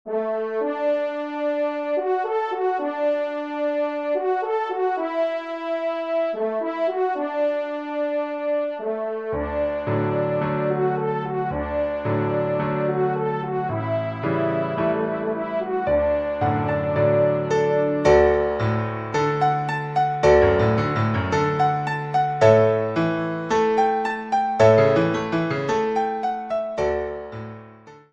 Pupitre Piano